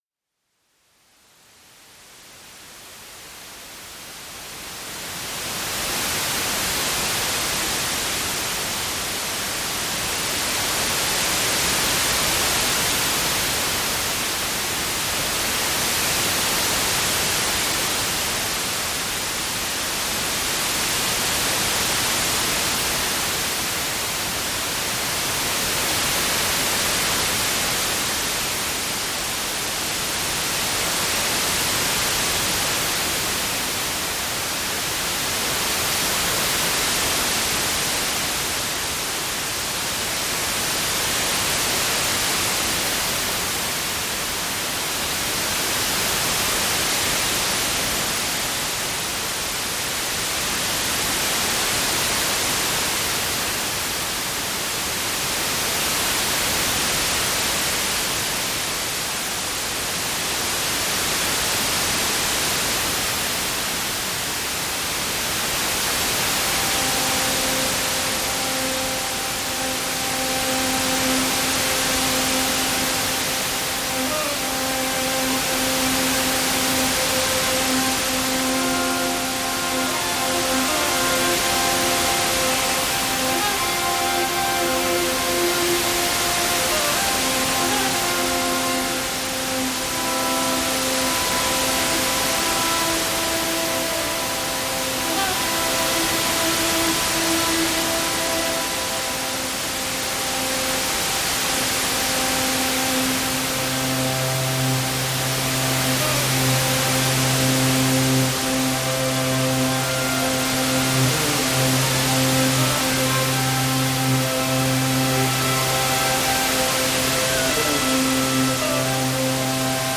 Klarer Kopf: Wege aus dem Trauma - White Noise Sleep Waves: 15 Minuten sanftes Rauschen für Tiefschlaf & Entspannung
In dieser Episode erwartet dich eine 15-minütige White Noise Sleep Waves-Aufnahme, die mit hochwertigen Klangquellen in Ableton Live erstellt wurde und sich ideal für Tiefschlaf, Meditation und Entspannung eignet.
🌙 Was macht diese Aufnahme besonders? 🎶 Gleichmäßiges weißes Rauschen (20 Hz – 20 kHz) für optimale Maskierung von Umgebungsgeräuschen 🎛 Weiche Modulationen & subtile Bewegung für eine natürliche Wahrnehmung ohne Störgeräusche 🌀 Langsame Auto-Pan-Effekte für ein angenehmes Raumgefühl 🎧 Keine plötzlichen Frequenzwechsel – dein Gehirn kann sich darauf entspannen